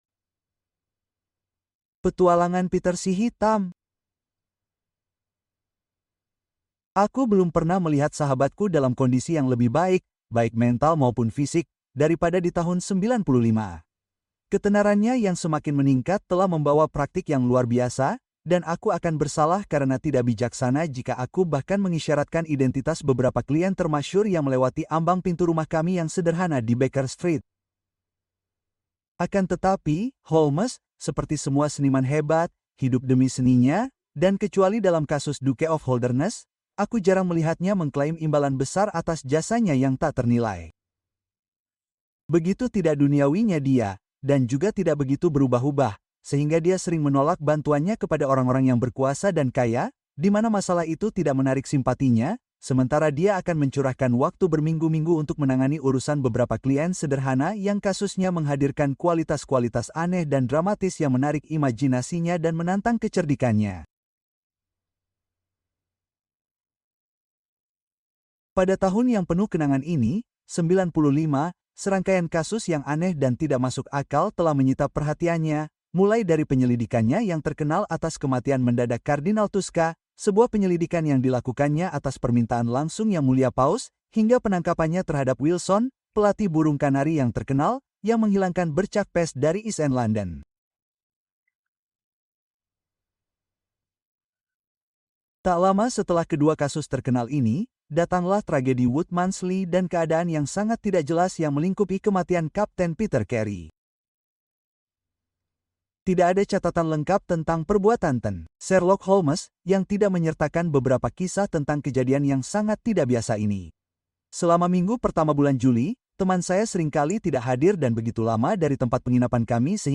The Beetle Part One: Outsold Dracula! (Audiobook)